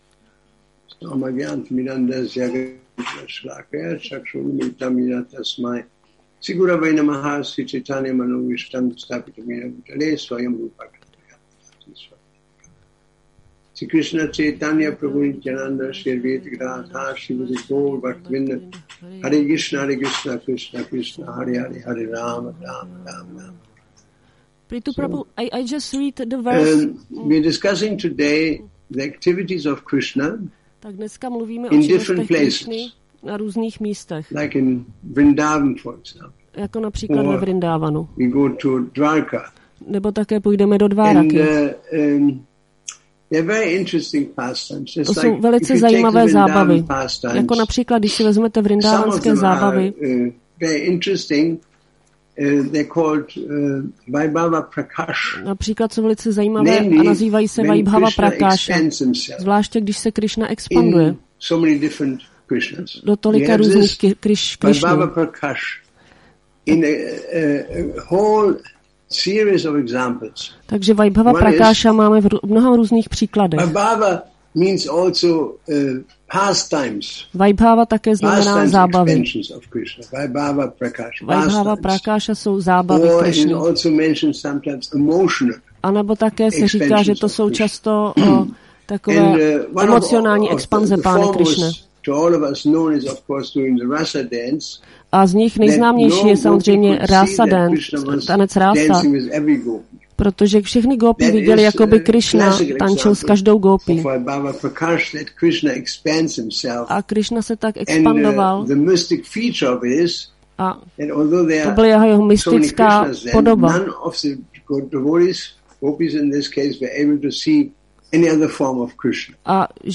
Přednáška SB-9.24.66